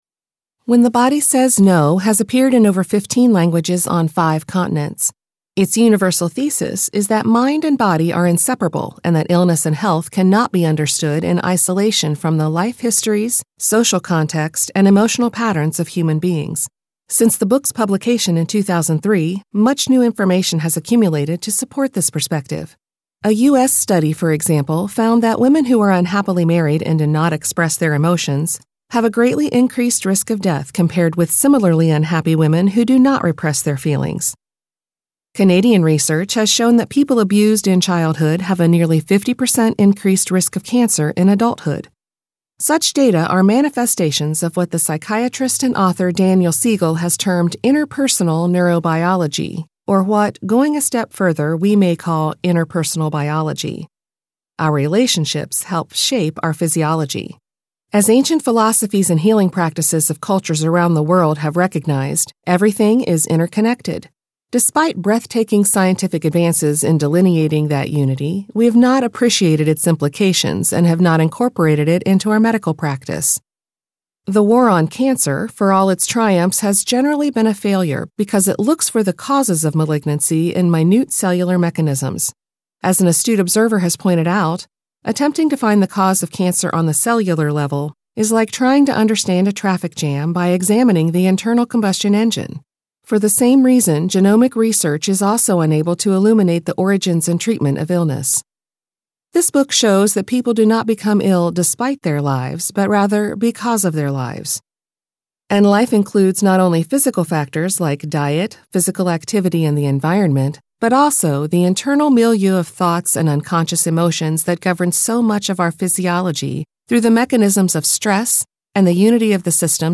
Self-help Reads
Accent: American (Midwest)